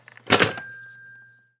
Play Phone Slam - SoundBoardGuy
Play, download and share Phone Slam original sound button!!!!
phone-slam.mp3